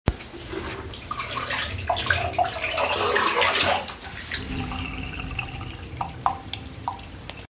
Cambiando líquido de un recipiente a otro
Grabación sonora del sonido que se produce cuando, mediante diferentes vasos de precipitado o continentes cualquiera, se cambia el líquido que posee uno de ellos a otro.
Sonidos: Agua
Sonidos: Acciones humanas